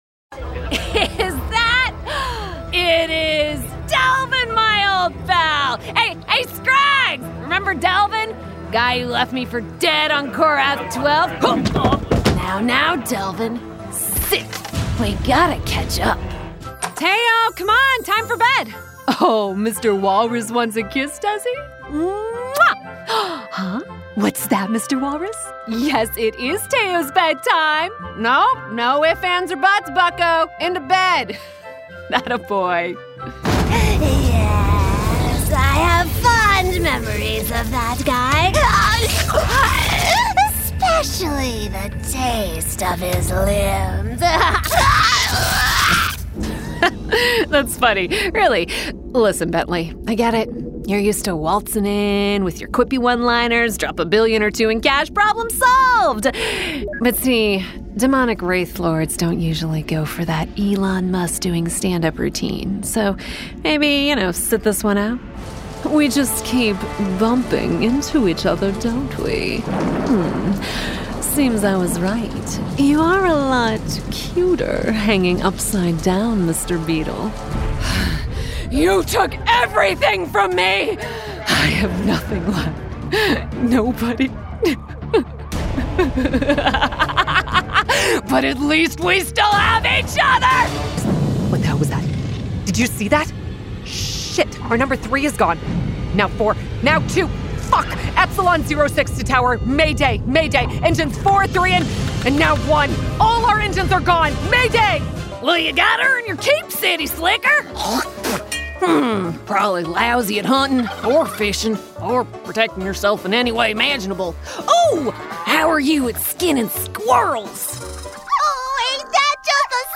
Demo
Teenager, Young Adult, Adult, Child
ANIMATION 🎬
creatures